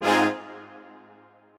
strings12_11.ogg